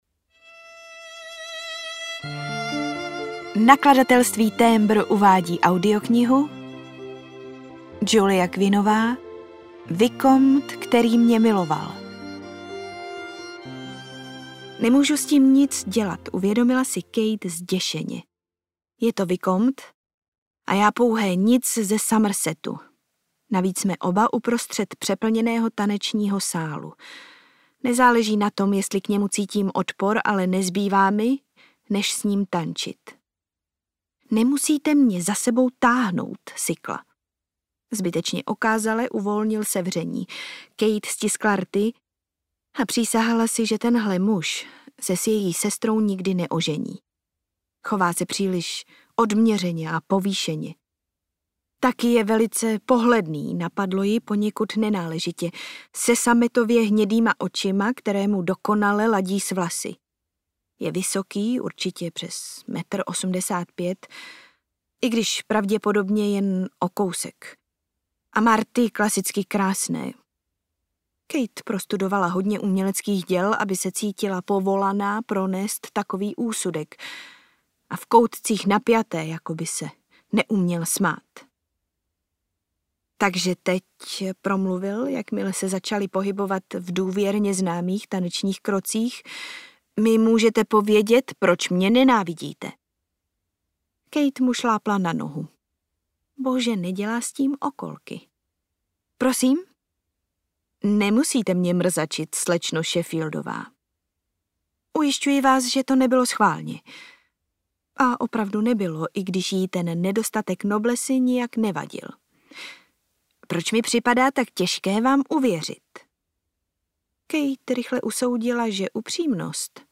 Vikomt, který mě miloval audiokniha
Ukázka z knihy